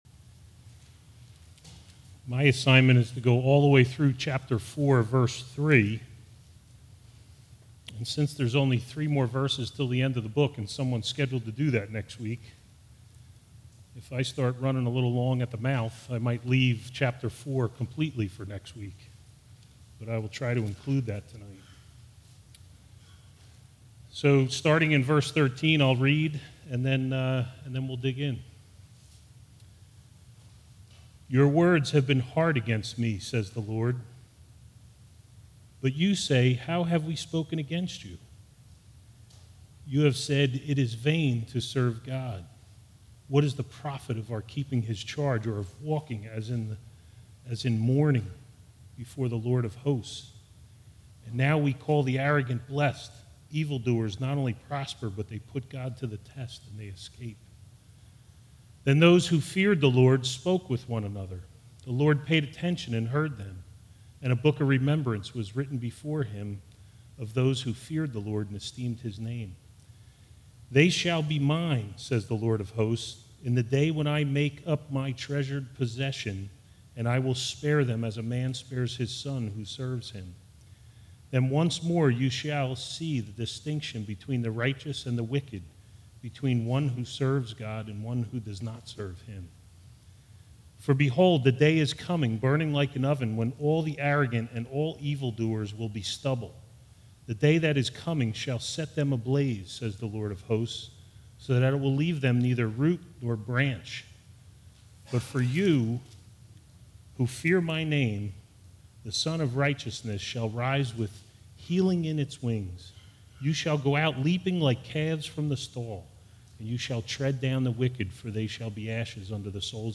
All Sermons Malachi 3:13-4:3